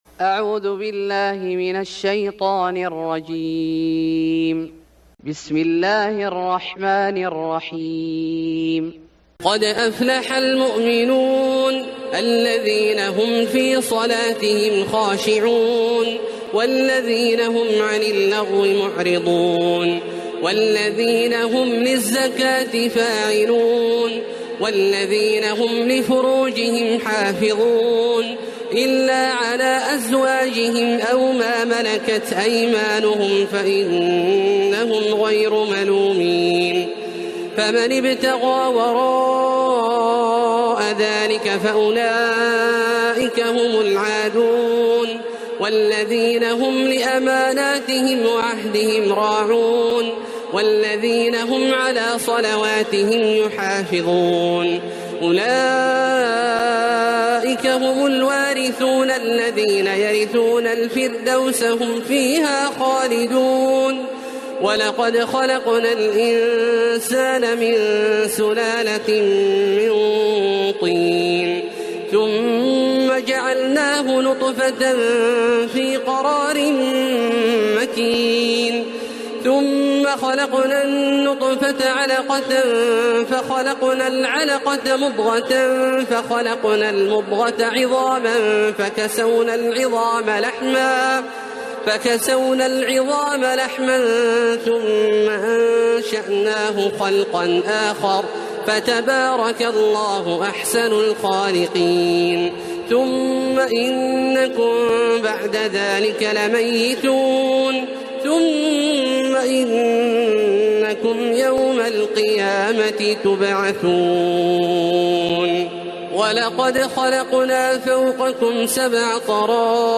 سورة المؤمنون Surat Al-Mu'minun > مصحف الشيخ عبدالله الجهني من الحرم المكي > المصحف - تلاوات الحرمين